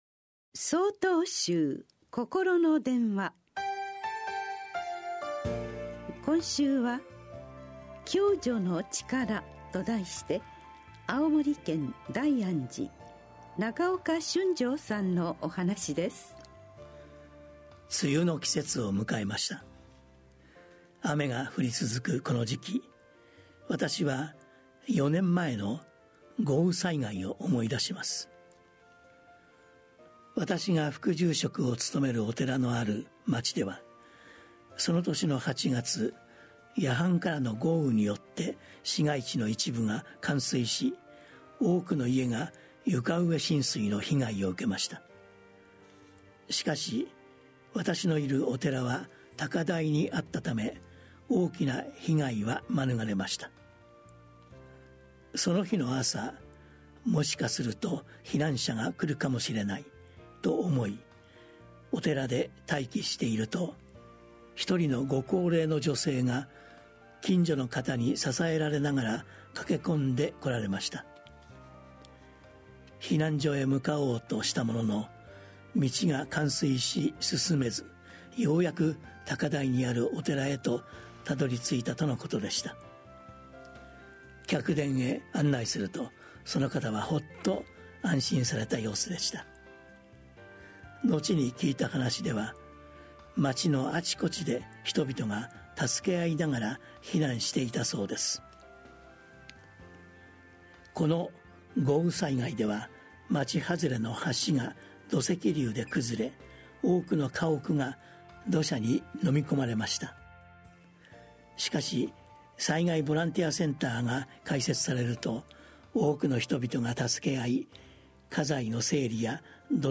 曹洞宗では毎週、わかりやすい仏教のお話（法話）を、電話と音声やポッドキャストにて配信しています。